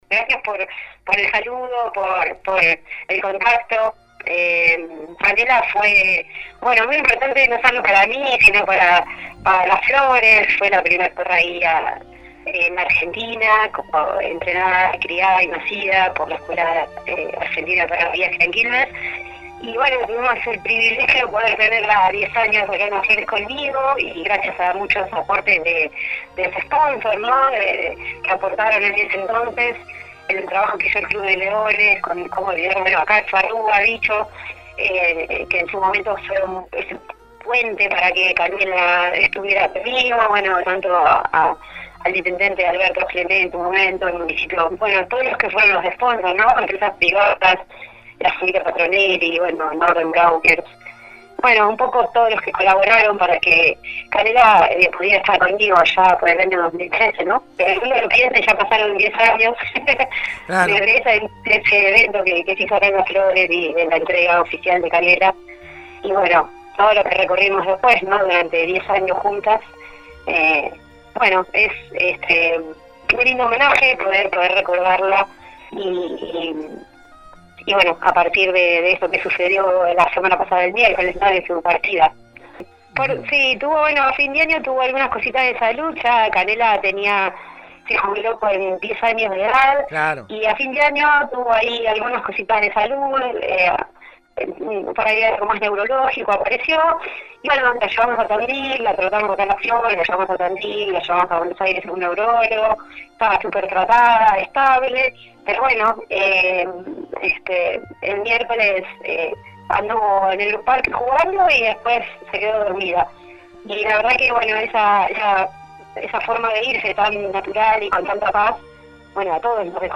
Durante la entrevista